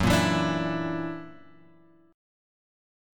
F#+7 chord {2 1 2 3 3 x} chord